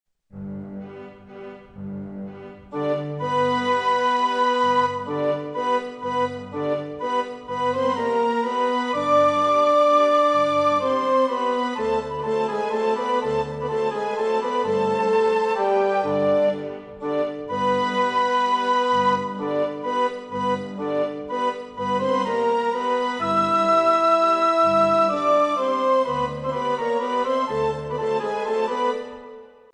Ed ora, lo stesso brano, in un andamento più lento:
La durata effettiva dei suoni è invece diversa nelle due versioni: più breve nella prima perché in andamento allegro, più lunga nella seconda, perché in andamento lento.